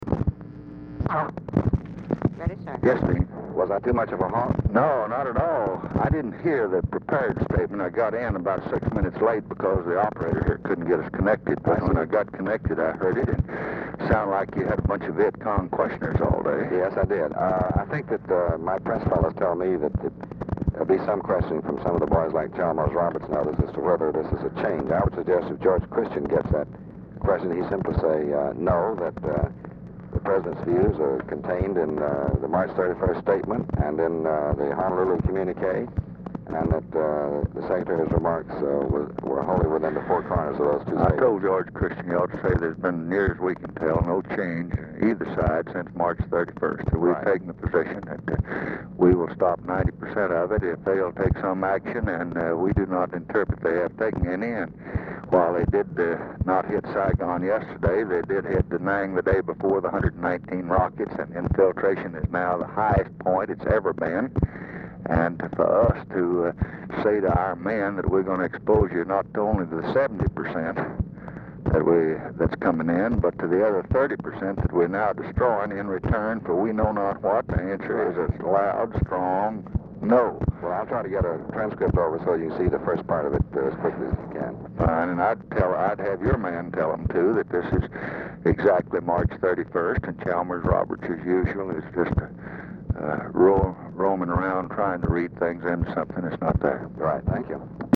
Telephone conversation # 13220, sound recording, LBJ and DEAN RUSK, 7/30/1968, 11:54AM | Discover LBJ
Format Dictation belt
Location Of Speaker 1 Mansion, White House, Washington, DC
Specific Item Type Telephone conversation Subject Defense Diplomacy Press Relations Lbj Speeches And Statements Vietnam Vietnam Criticism